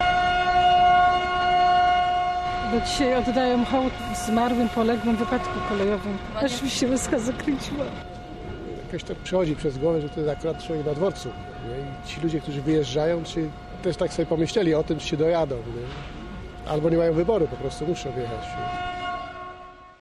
Syreny w hołdzie ofiarom wypadku
Na stacji Poznań Główny w hołdzie kolejarzom sygnał dało 12 lokomotyw.